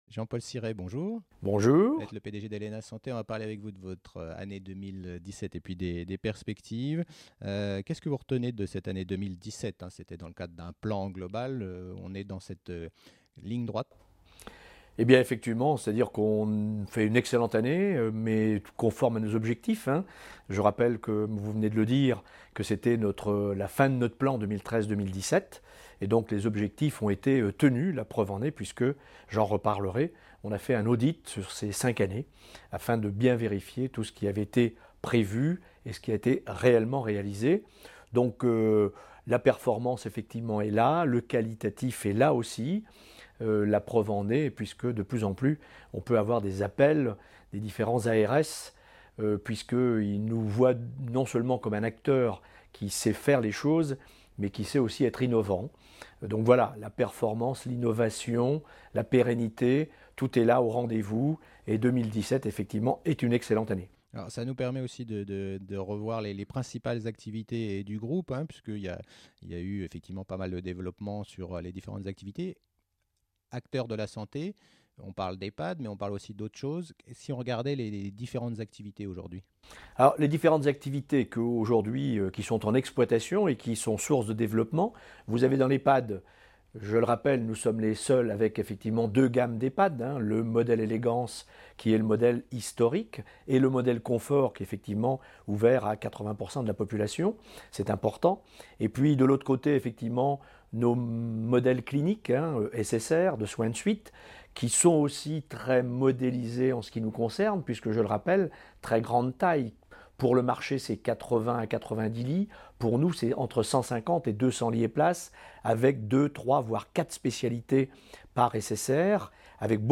Je parle de cette année et des perspectives avec mon invité